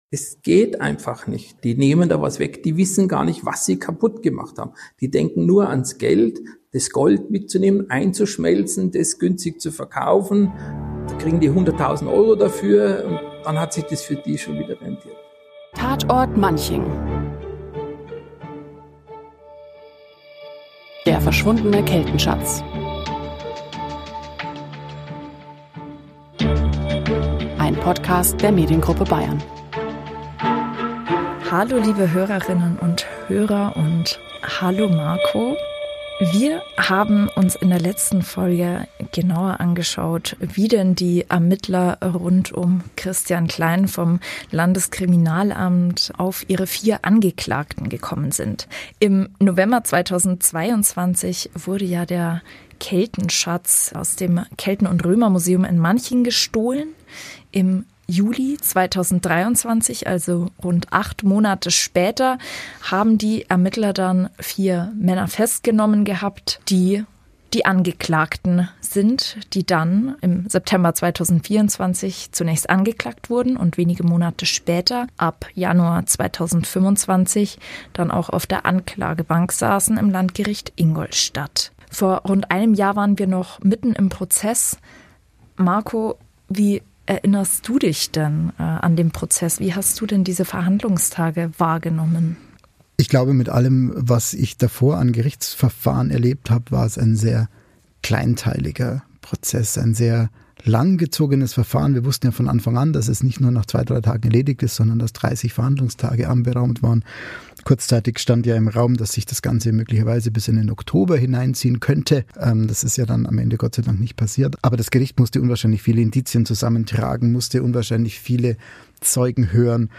Die Hosts des Podcasts wagen daher auch einen kurzen Ausblick auf das, was nun folgt: Die Angeklagten haben Revision eingelegt, der Fall liegt aktuell beim Bundesgerichtshof (BGH).